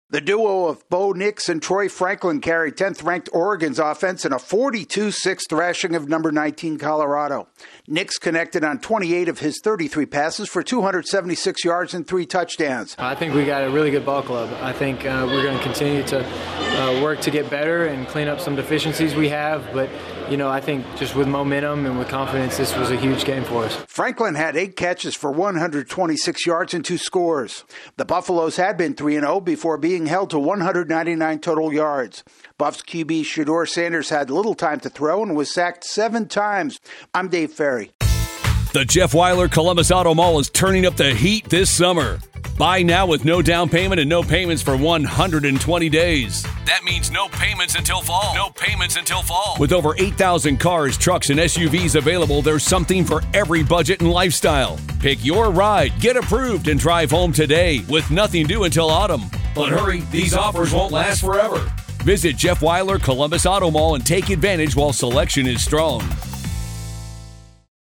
Oregon is in Prime form against Colorado. AP correspondent